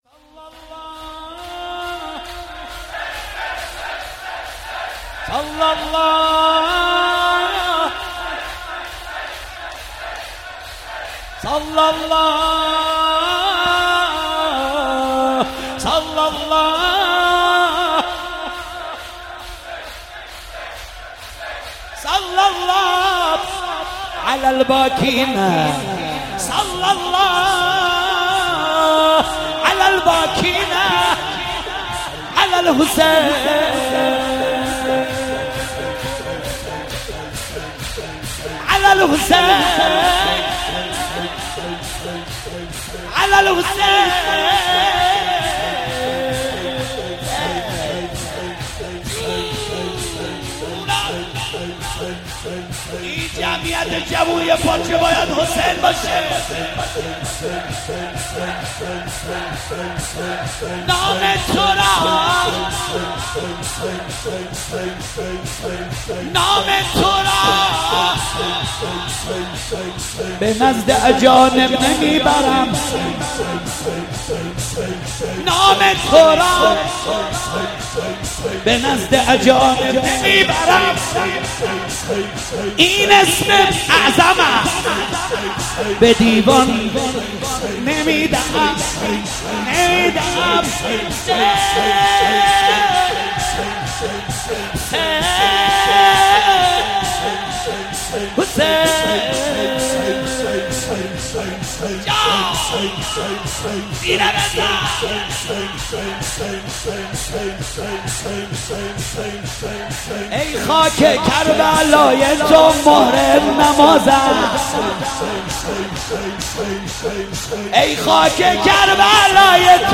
در مسجد مصلی اصفهان برگزار شد
روضه
شور زیبا